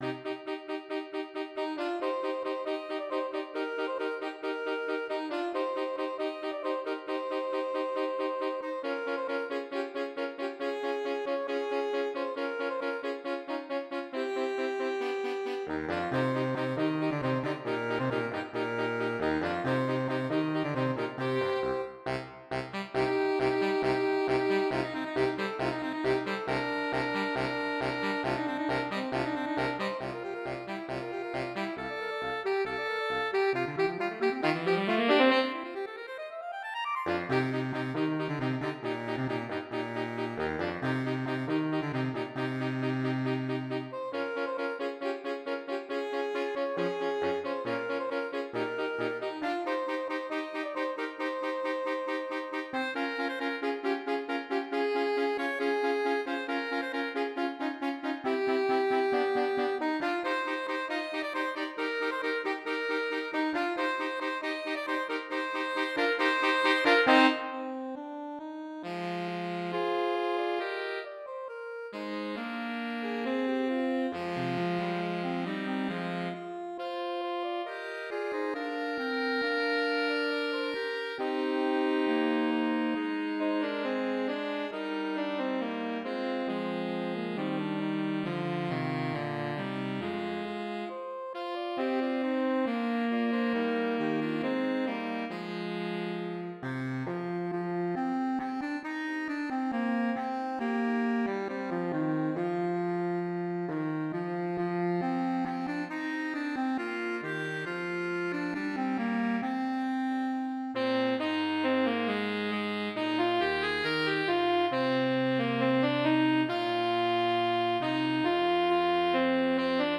5 SATB. Fast and exciting.